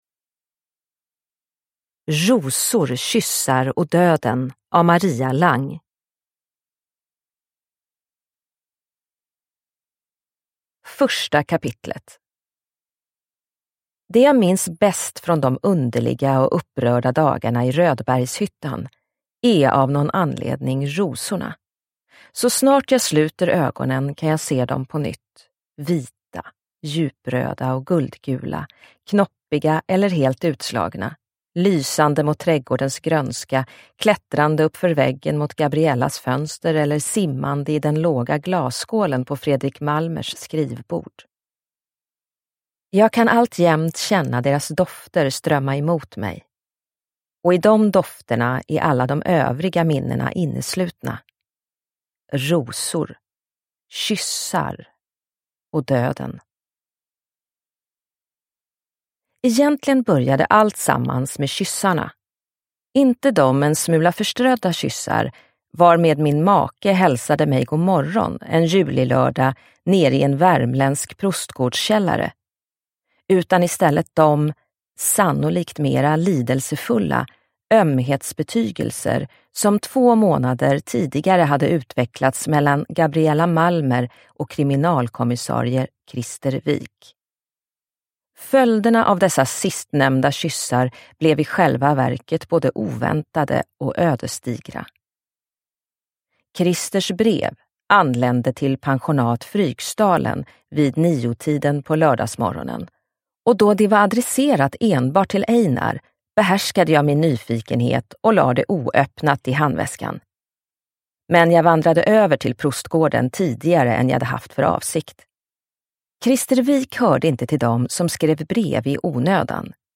Rosor, kyssar och döden – Ljudbok – Laddas ner